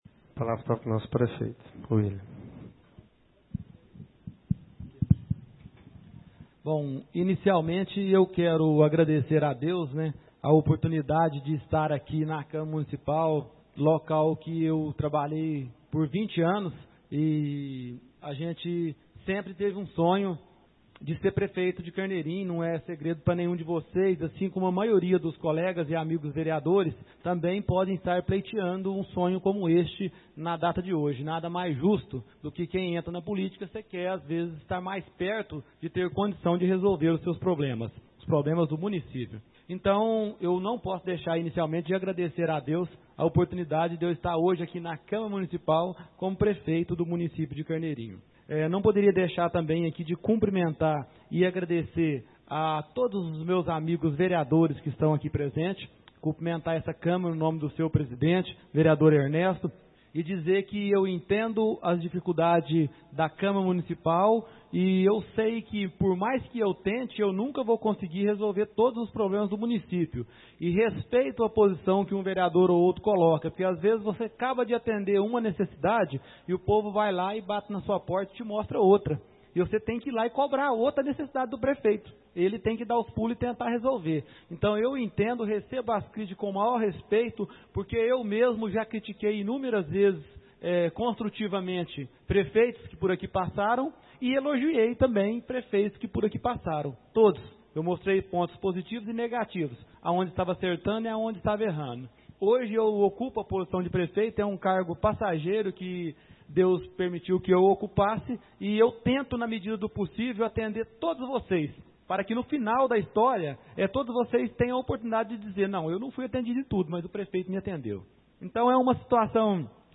Discurso prefeito 18ª Reunião de 2013 — Camara Carneirinho - MG